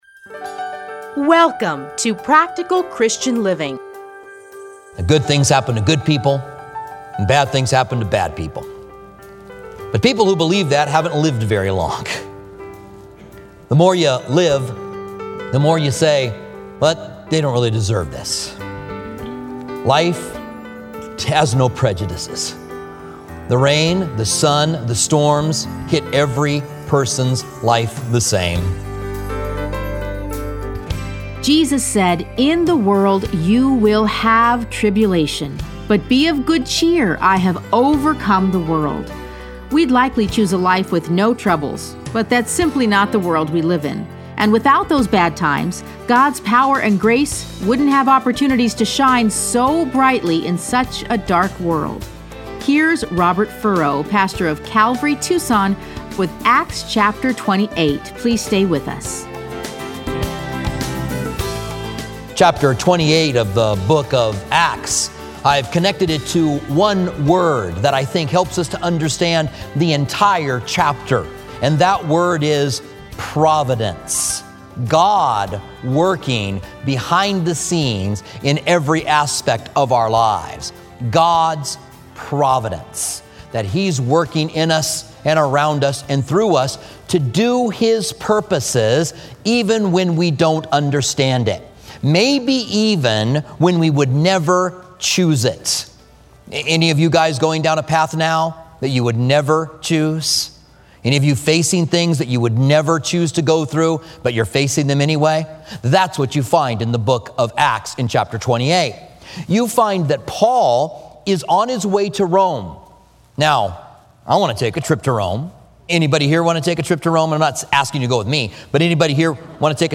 Listen to a teaching from Acts 28.